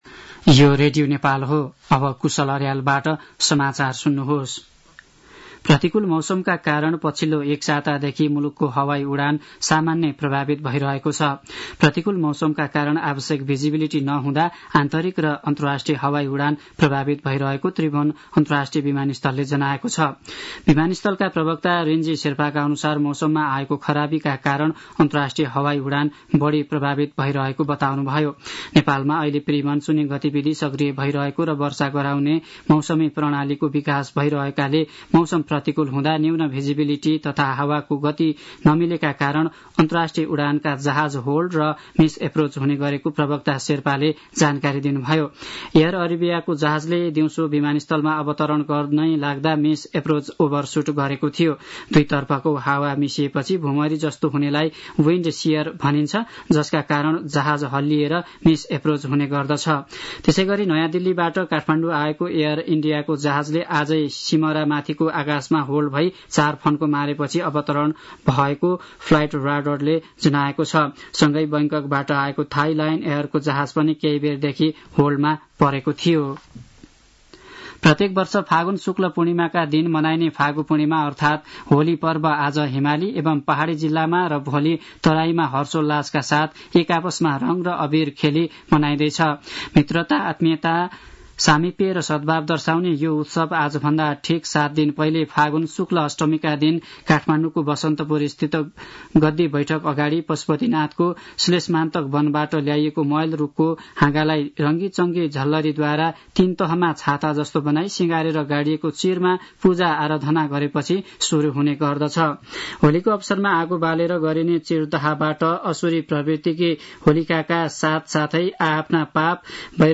साँझ ५ बजेको नेपाली समाचार : ३० फागुन , २०८१